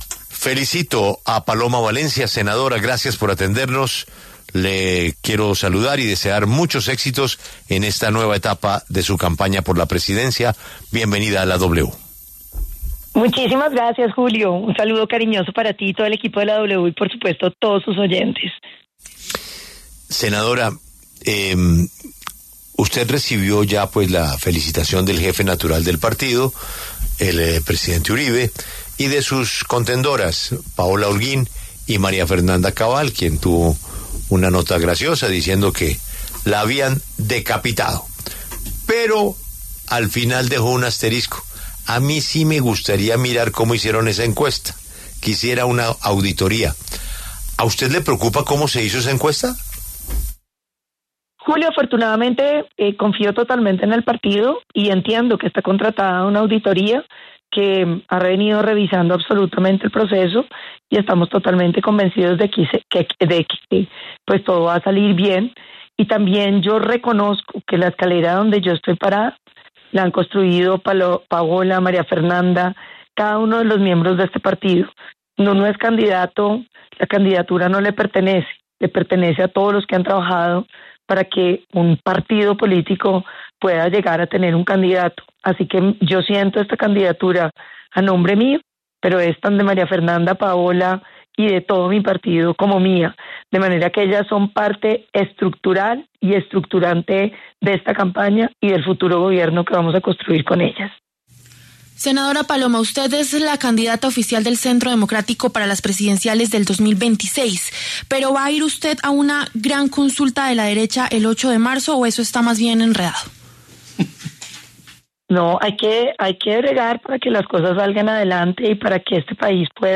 La senadora Paloma Valencia habló en La W tras haber sido elegida candidata presidencial única del Centro Democrático.